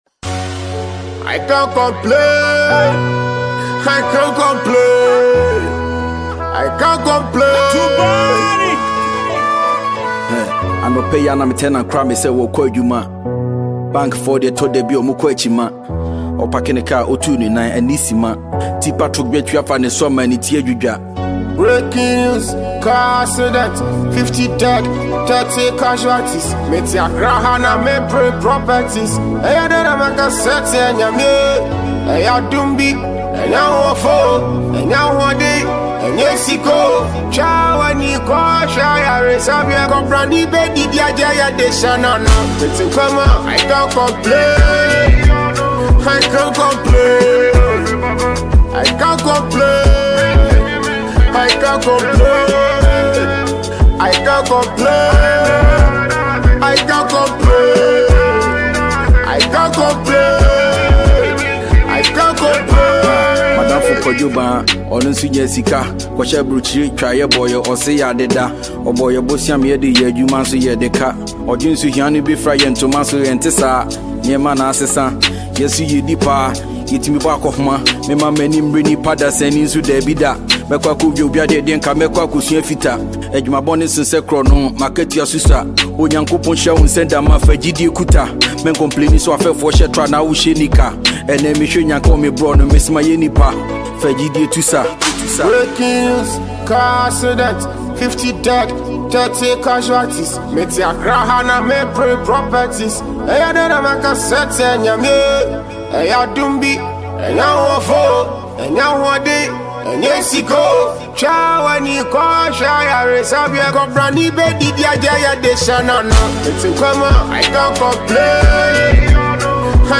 Ghana Music
Ghanaian rapper and songwriter